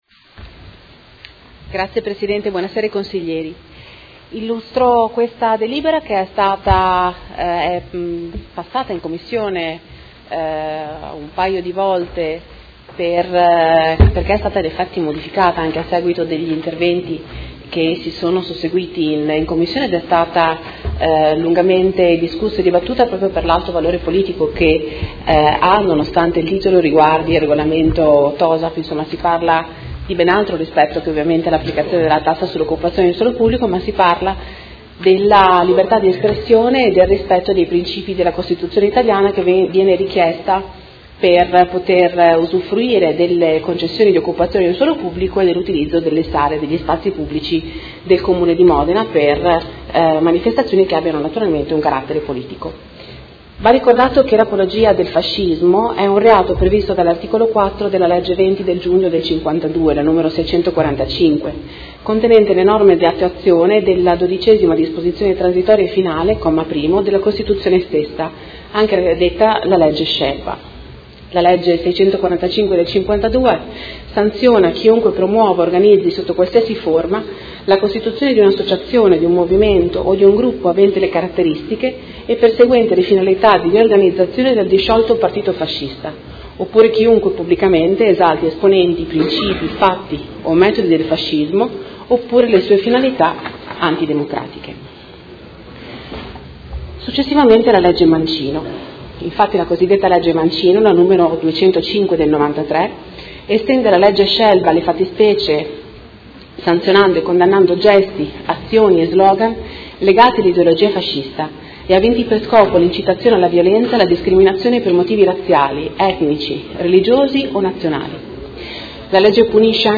Seduta del 24/01/2019. Proposta di deliberazione: Concessione di spazi pubblici e di sale - Riferimento al rispetto della Costituzione della Repubblica Italiana e in particolare ai principi e valori della Resistenza e dell'antifascismo e alla condanna ad ogni forma di discriminazione – Modifica del Regolamento comunale per l'applicazione della tassa per l'occupazione di spazi ed aree pubbliche e per il rilascio delle concessioni di suolo pubblico